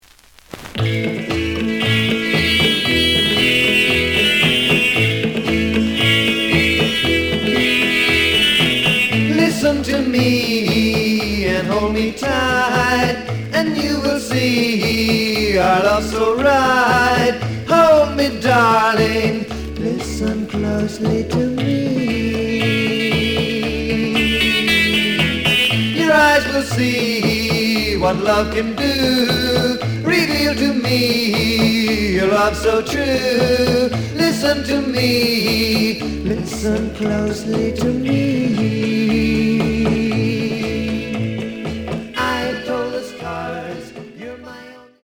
The audio sample is recorded from the actual item.
●Genre: Rhythm And Blues / Rock 'n' Roll
Some noise on beginning of both sides due to heat damage.